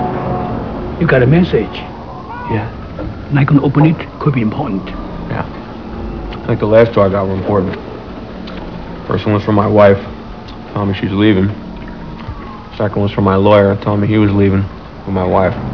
bruce willis talking about wives and lawyers funny 169kb